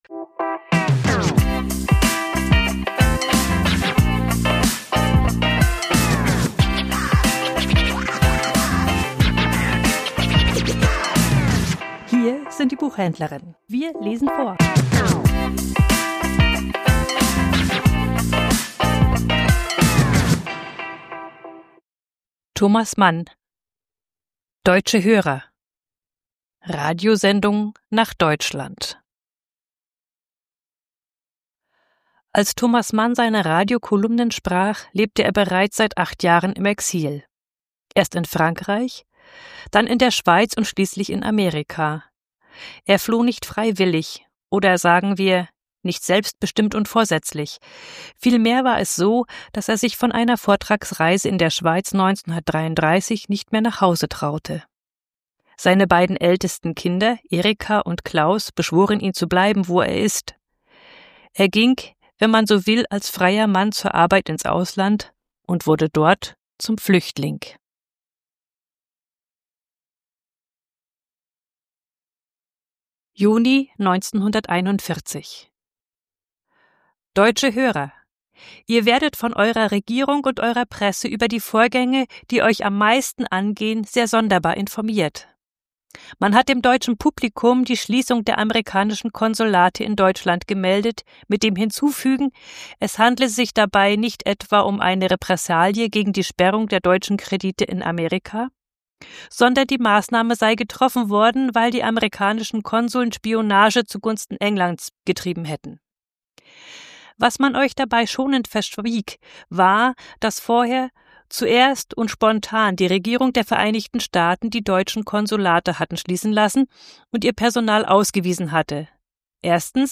Vorgelesen: Deutsche Hörer ~ Die Buchhändlerinnen Podcast